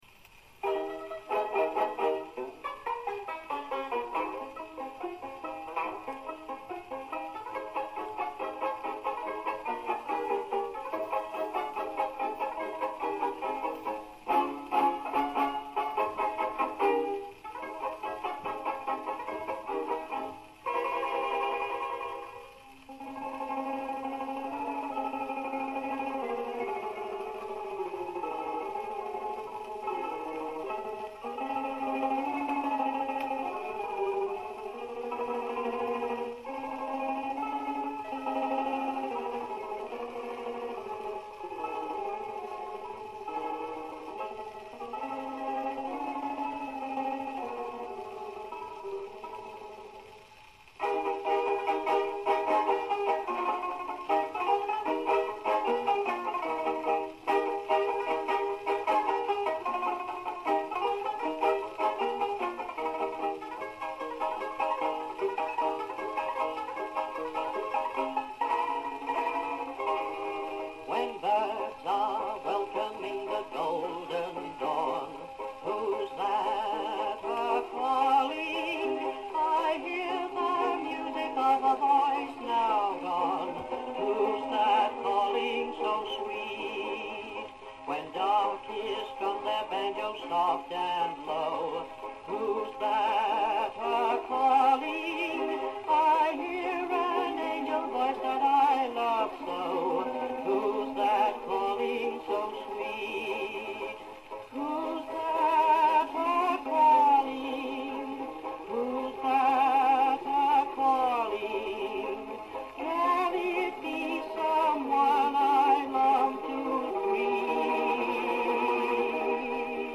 on tenor banjo.